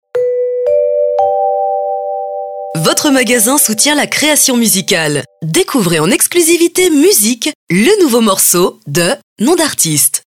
jingle-accueil.mp3